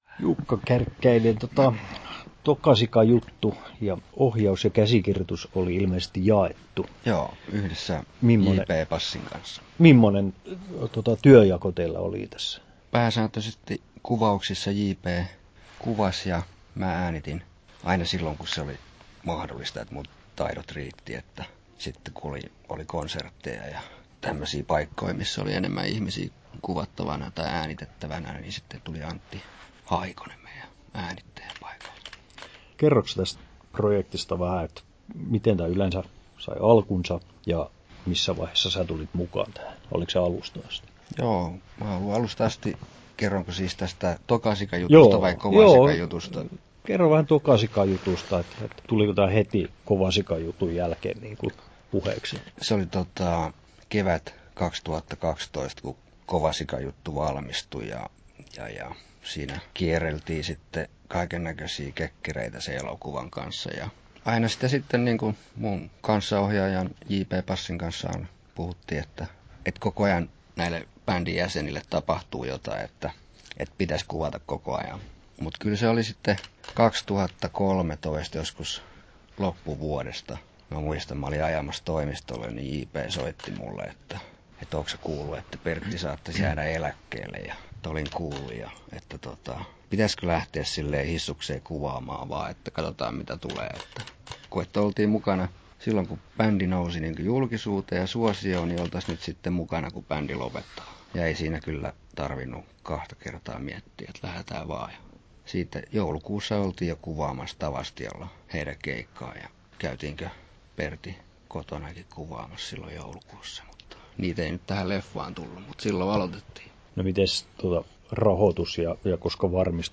Haastattelussa
9'58" Tallennettu: 10.10.2017, Turku Toimittaja